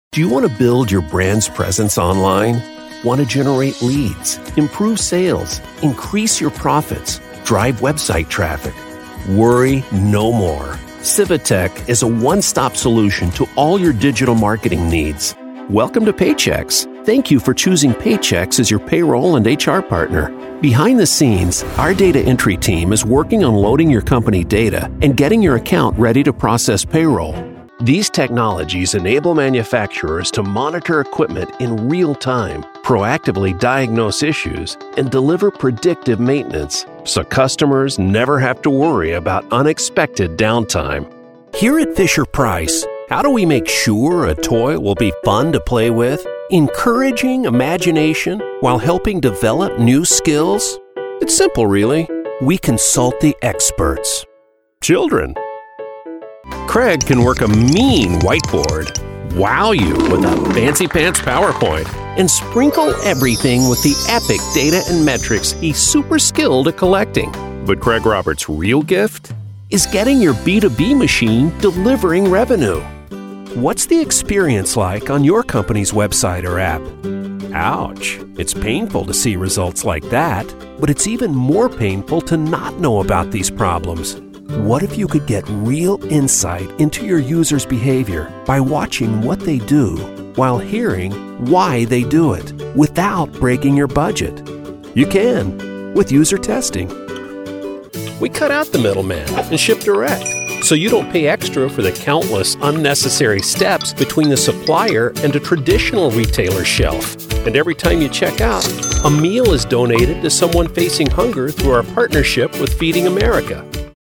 English (American)
Natural, Distinctive, Versatile, Friendly, Warm
Explainer